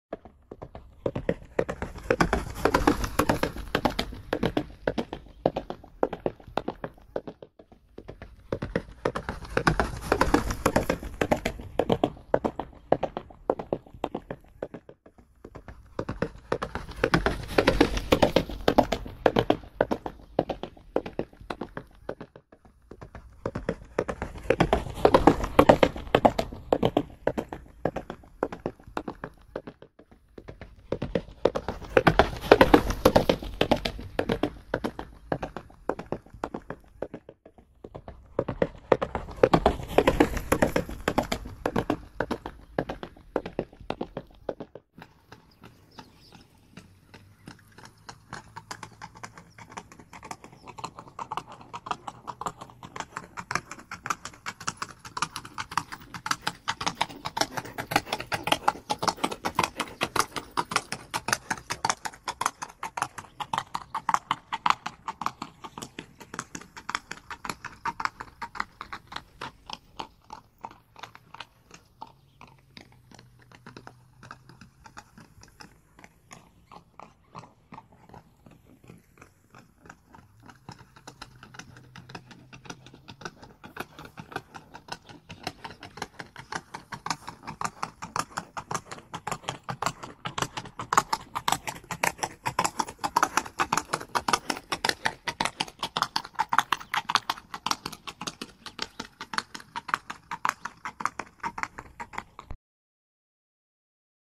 دانلود صدای دویدن اسب – یورتمه اسب 1 از ساعد نیوز با لینک مستقیم و کیفیت بالا
جلوه های صوتی